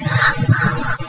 EVP 4: Mama